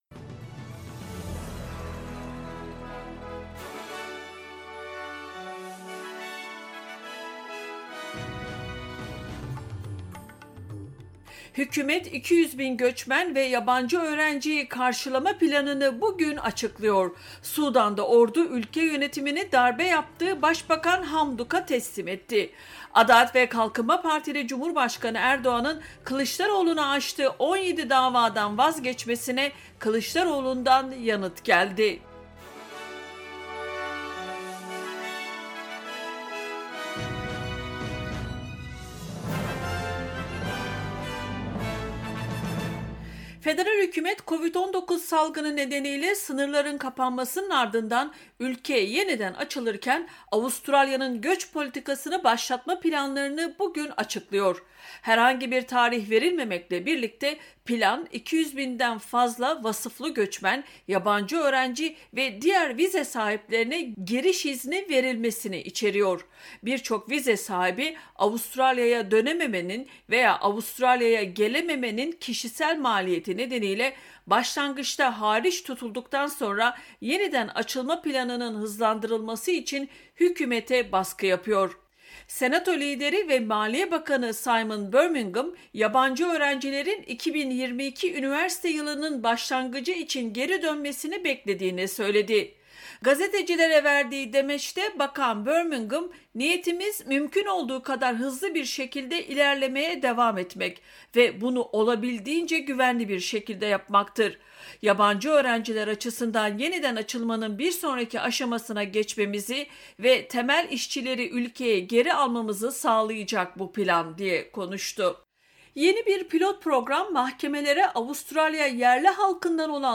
SBS Türkçe Haberler 22 Kasım